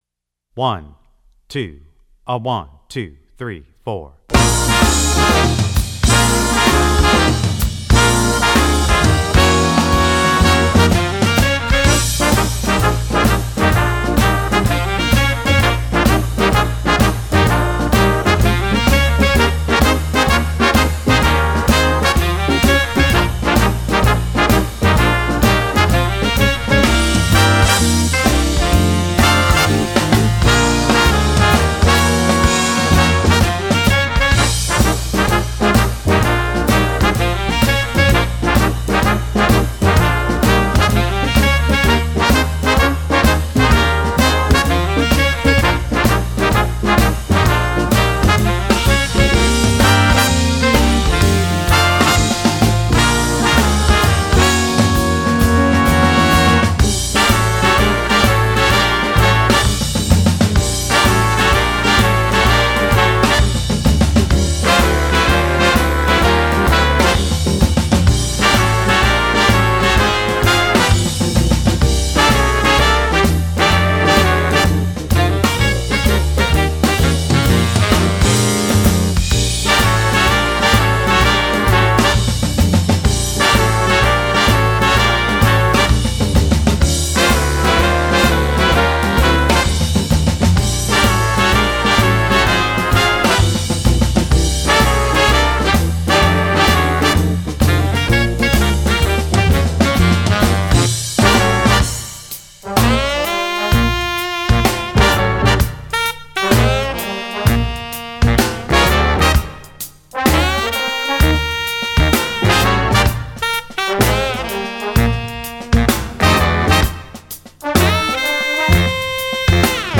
Gattung: für Drums
Besetzung: Instrumentalnoten für Schlagzeug/Percussion